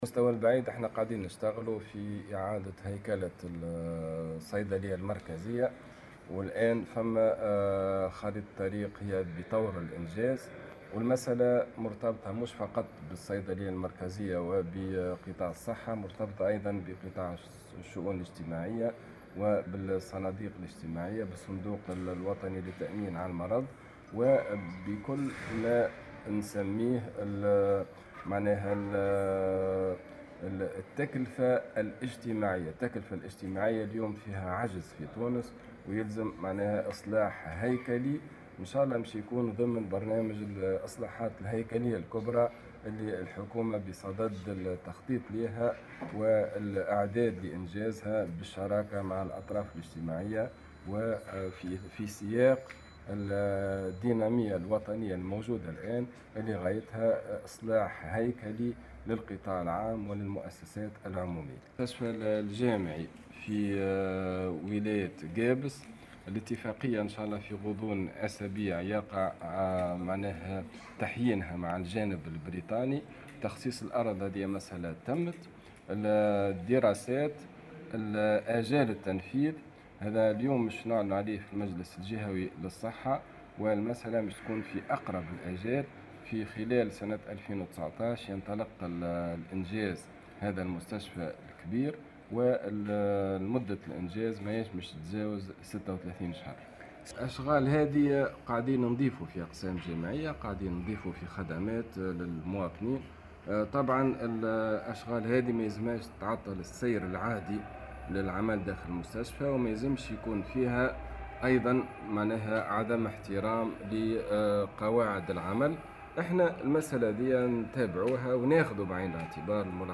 وأضاف الوزير في تصريح لمراسل "الجوهرة أف أم" خلال إشرافه على المجلس الجهوي للصحة بقابس، أن الوزارة تعمل على المدى الطويل لإعادة هيكلة الصيدلية المركزية بالإشتراك مع الأطراف الإجتماعية وذلك ضمن مخطط الإصلاحات الهيكلية الكبرى التي تعمل عليها الحكومة في القطاع العام والمؤسسات العمومية.